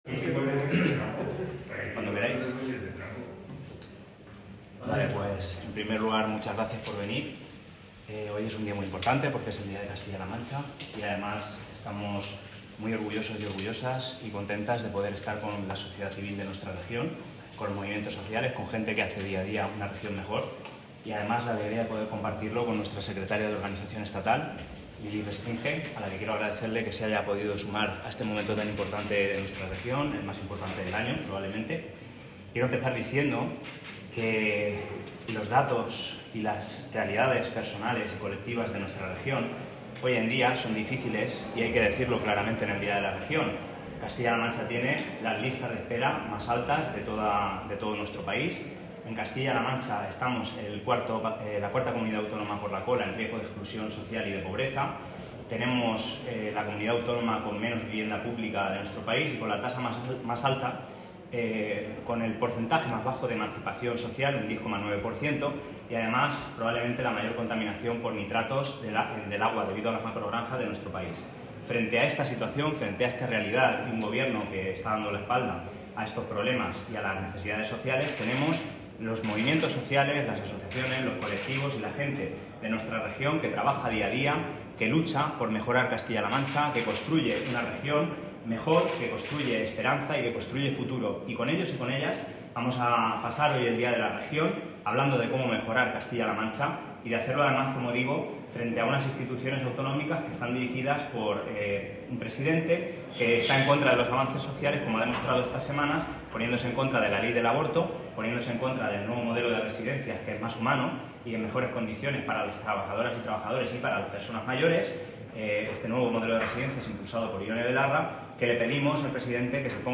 Podemos CLM ha celebrado en el barrio del Polígono de Toledo el Día de Castilla-La Mancha con más de un centenar de asistentes.